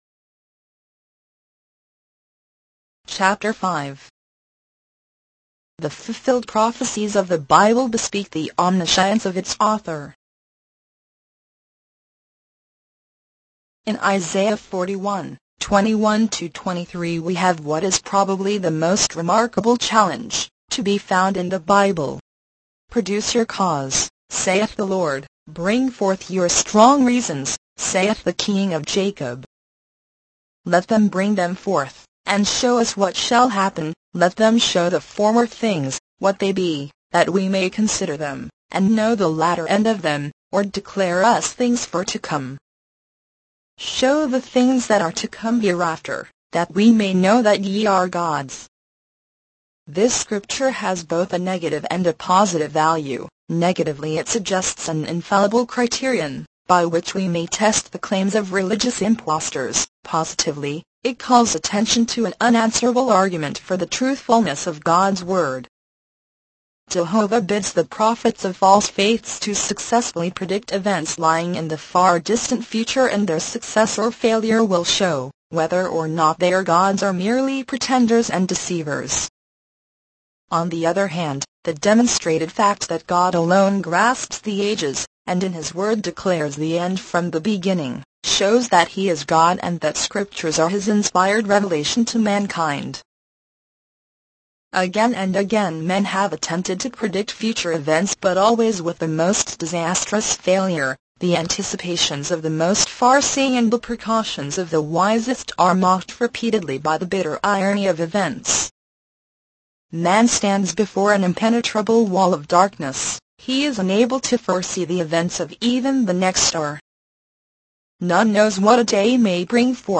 Free Christian audiobooks.
Free Christian Audio Books Digital Narration for the 21st Century